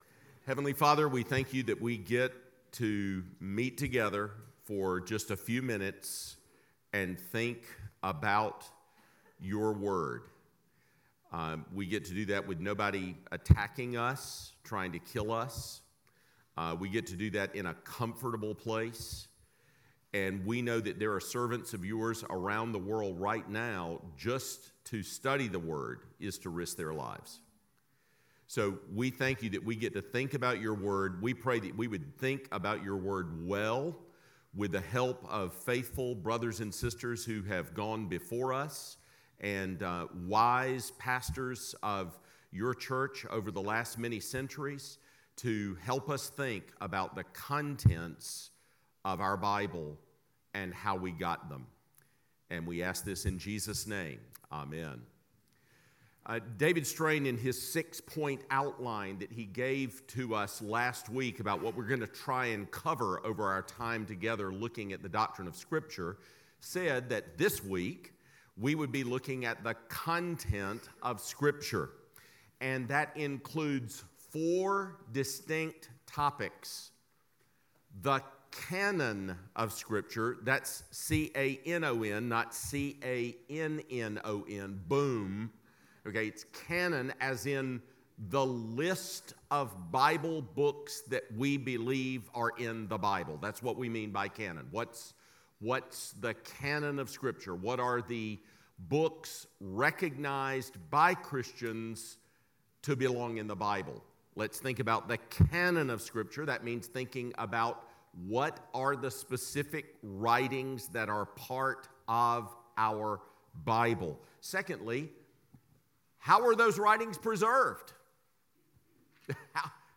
Miller Hall